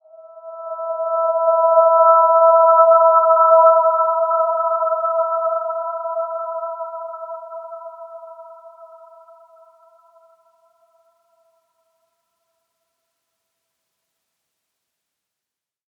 Dreamy-Fifths-E5-mf.wav